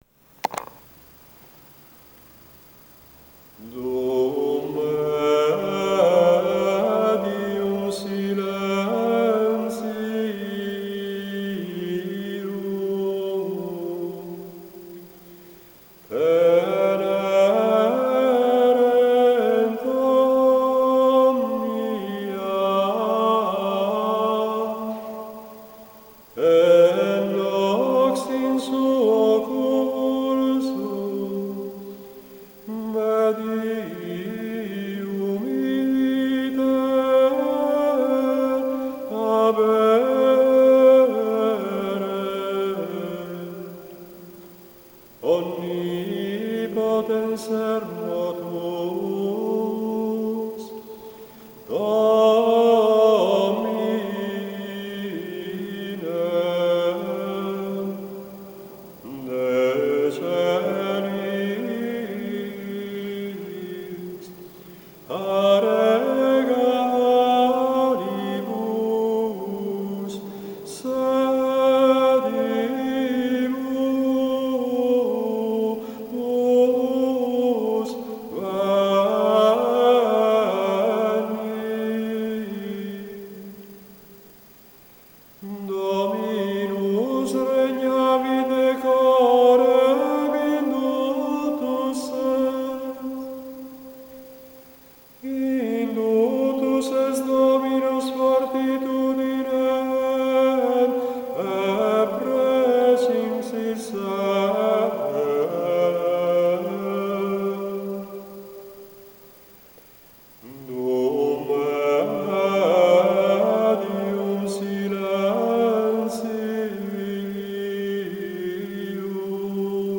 dum-medium-silentium-introito-gregoriano.mp3